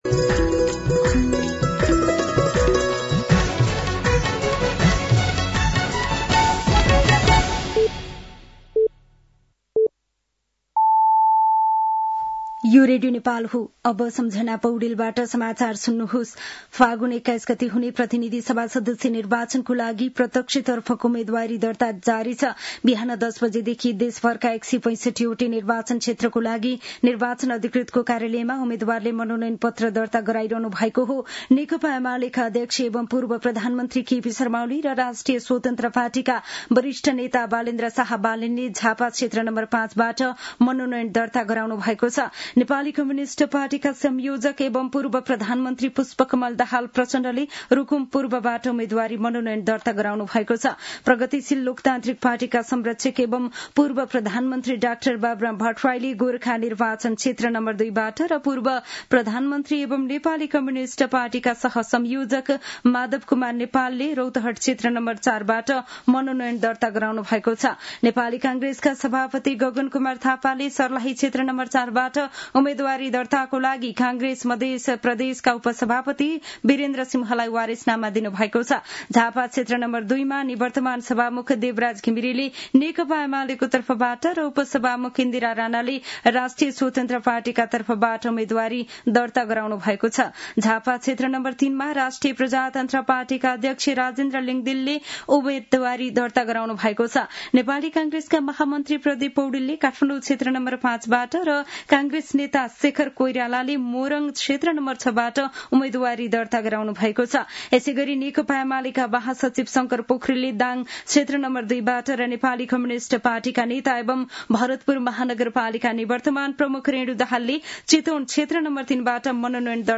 साँझ ५ बजेको नेपाली समाचार : ६ माघ , २०८२